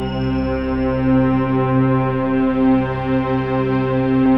SI1 BELLS05R.wav